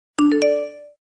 Стандартні оригінальні рінгтони